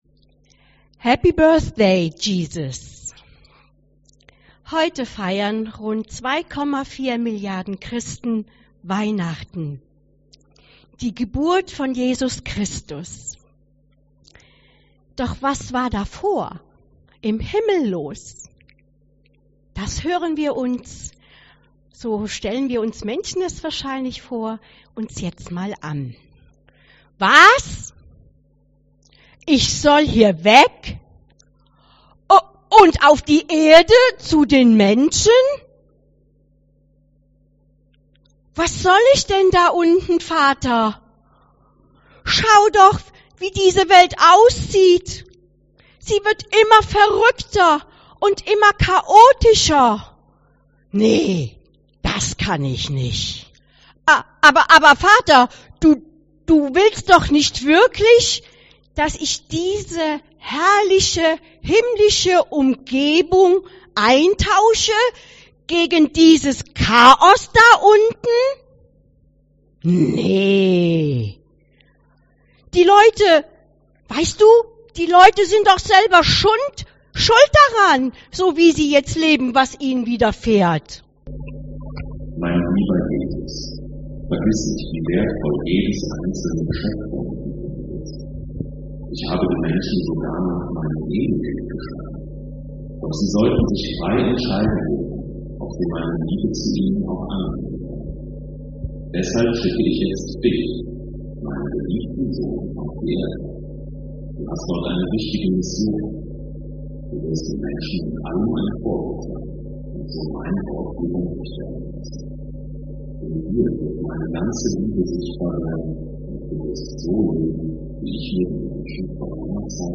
Bibeltext zur Predigt: Jesaja 9,5